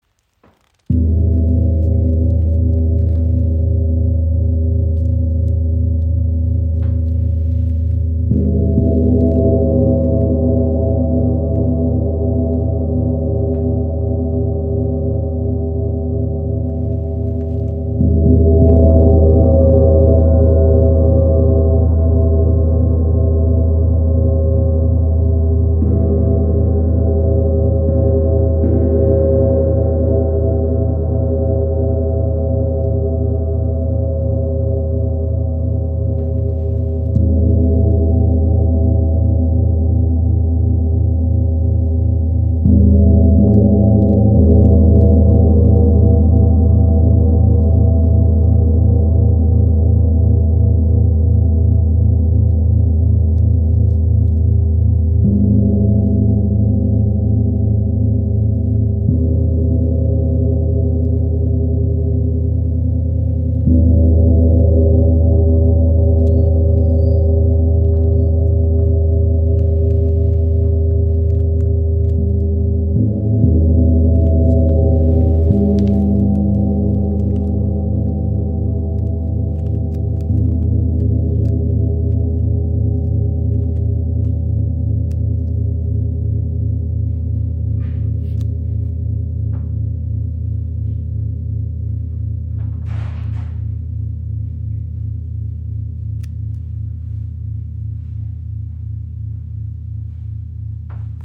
• Icon Bronze und Nickel-Silber erzeugen warme, harmonische Klänge.
Die Instrumente zeichnen sich durch harmonische Obertöne, warme Resonanz und ausgewogene Klangtiefe aus.